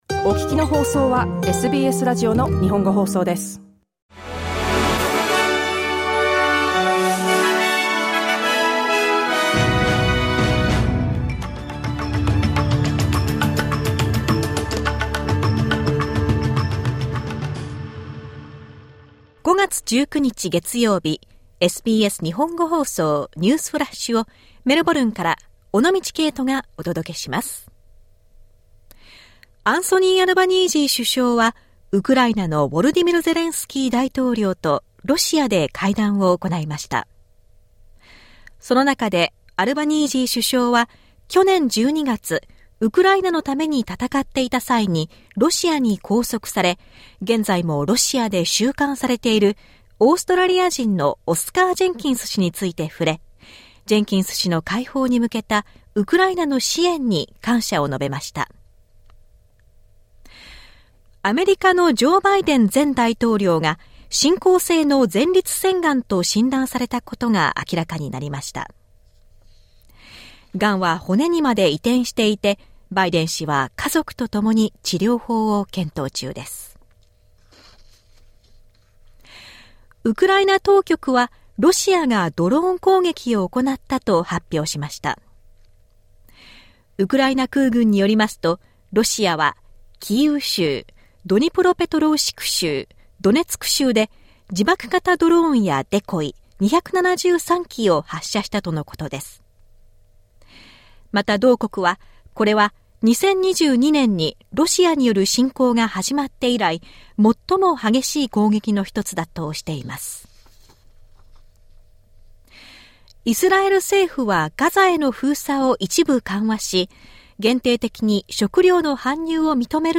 SBS日本語放送ニュースフラッシュ 5月19日 月曜日